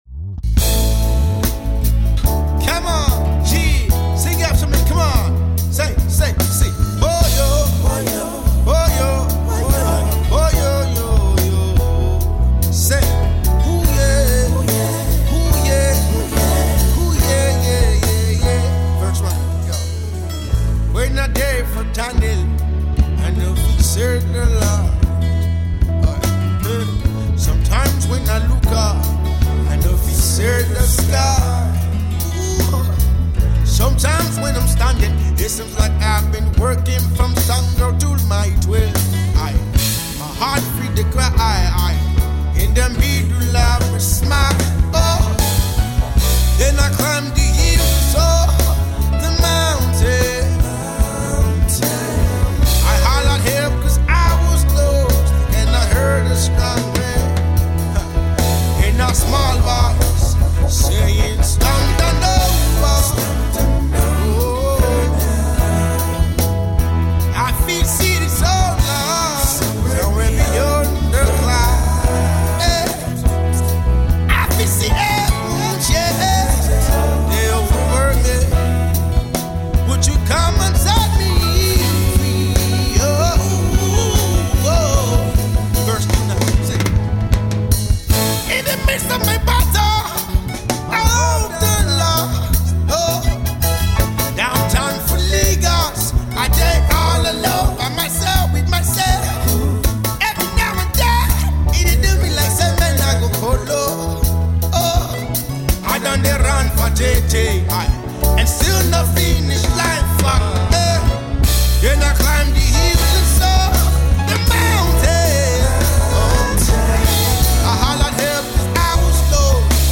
[Live Performance]